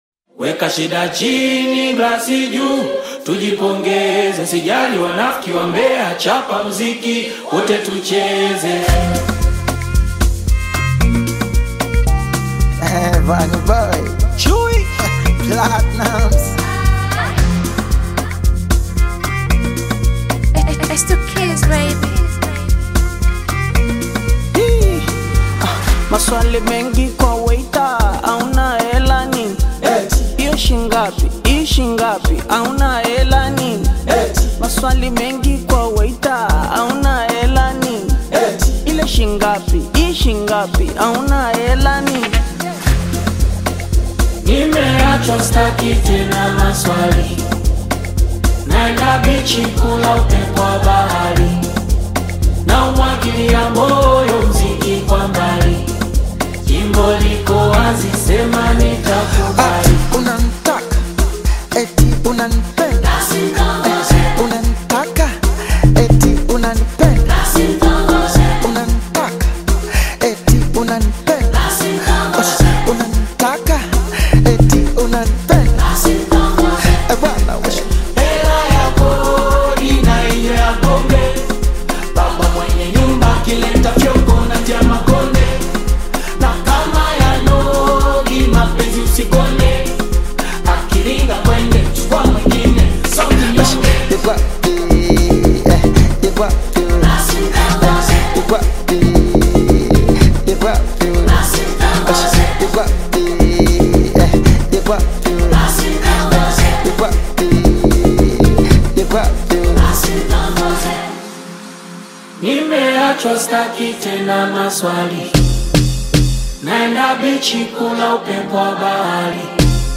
Amapiano Party track